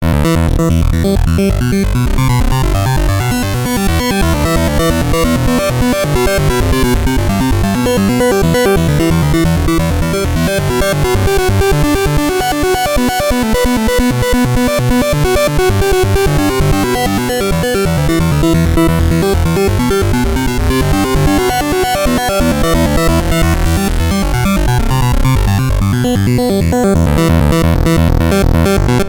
In following audio sample the oscillator phase offset between three pulse waveforms is slowly changed from 0..33% - once this value is reached, the resulting frequency is 3 times higher than the base frequency: